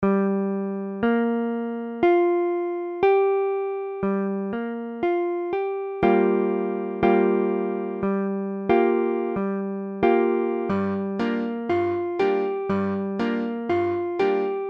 Tablature Gm7.abcGm7 : accord de Sol mineur septième
Mesure : 4/4
Tempo : 1/4=60
A la guitare, on réalise souvent les accords en plaçant la tierce à l'octave.
Gm7.mp3